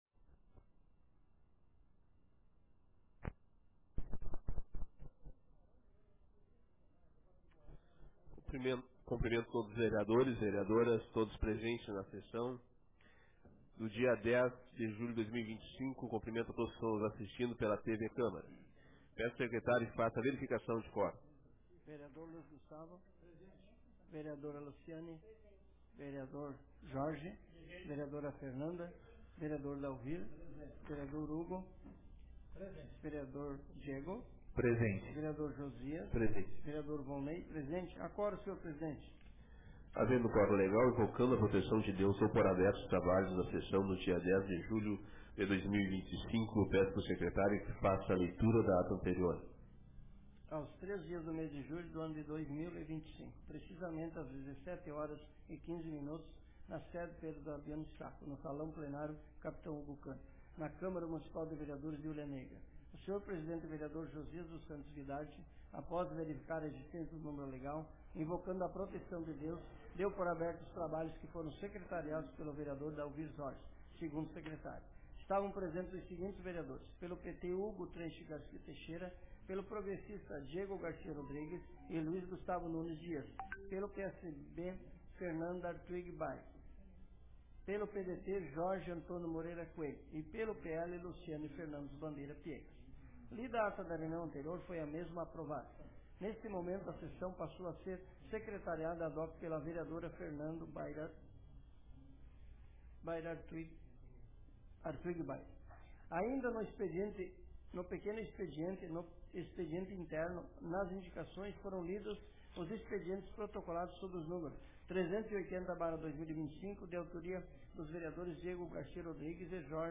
Sessão Ordinária da Câmara de Vereadores de Hulha Negra Data: 10 de julho de 2025